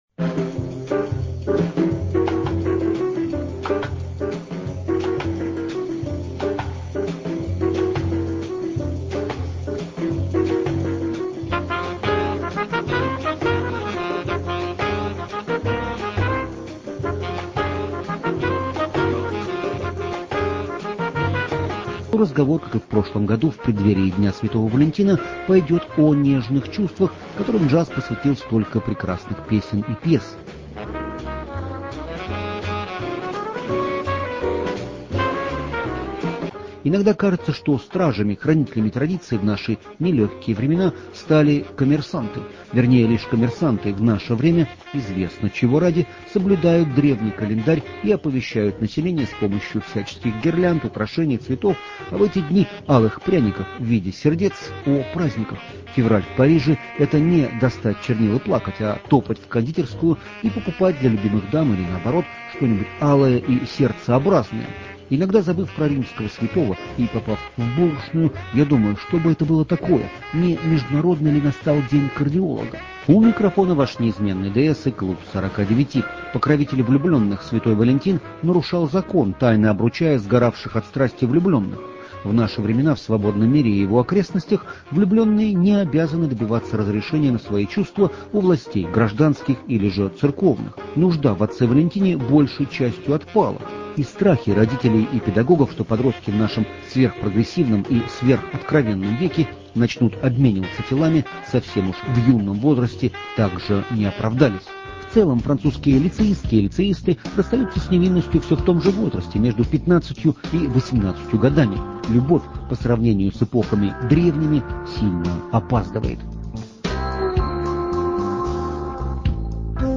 День Святого Валентина в джазе